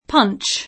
vai all'elenco alfabetico delle voci ingrandisci il carattere 100% rimpicciolisci il carattere stampa invia tramite posta elettronica codividi su Facebook punch [ingl. p 9 n © ] s. m. (in it.); pl. (ingl.) punches [ p 9^ n © i @ ] — italianizz. in ponce [ p 0 n © e ] (pl. ‑ci )